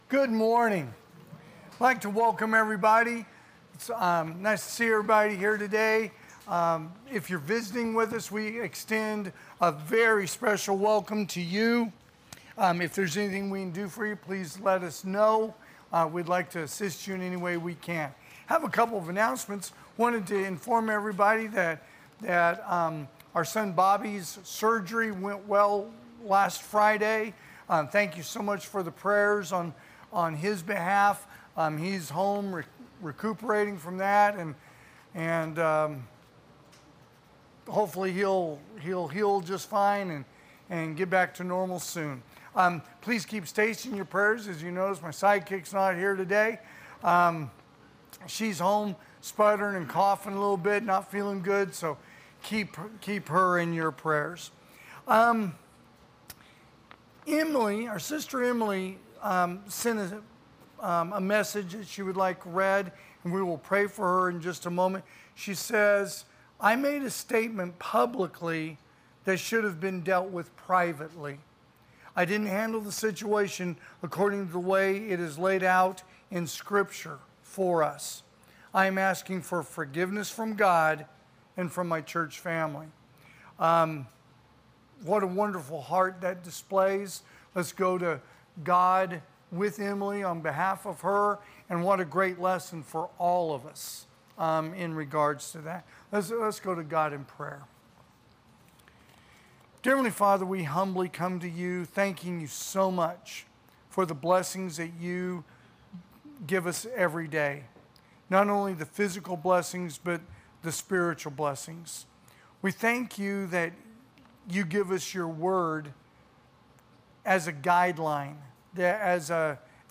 AM Worship
Sermons